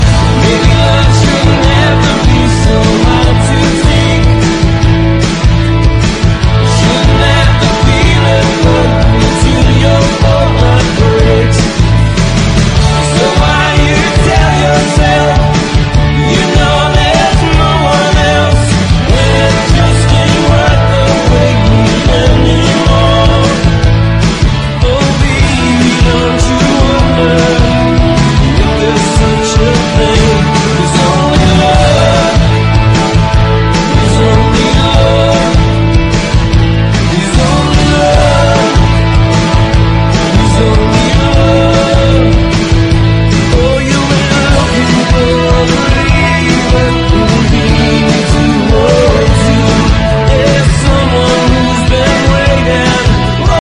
TEX-MEX
GARAGE ROCK (US)
オブスキュアなテキサス・ミュージック・コンピレーション！